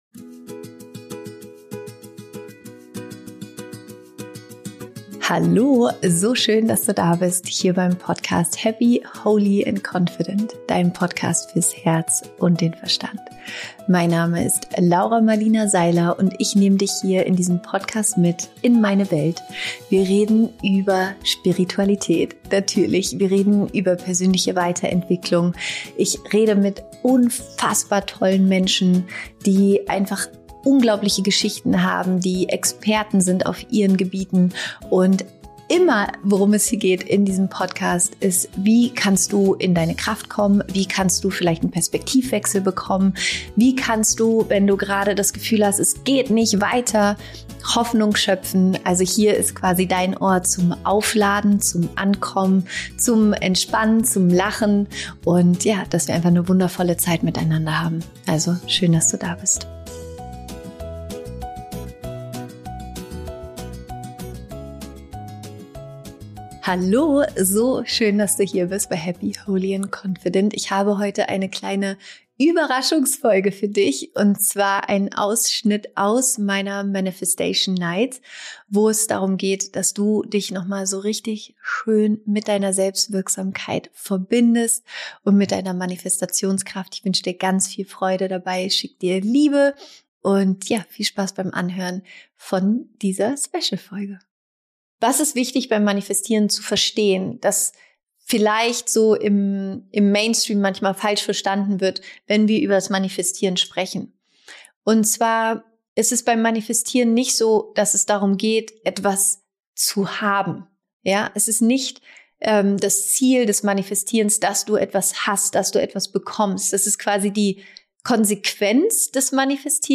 Du erfährst, wie du innere Blockaden löst und deine Wünsche in die Realität holst. Als Highlight wartet am Ende der Folge eine wunderschöne Meditation, die dich dabei unterstützt, ein energetisches Match für deine Träume zu werden – perfekt, um das Jahr kraftvoll abzuschließen und in ein erfülltes 2025 zu starten.